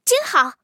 T43获得资源语音.OGG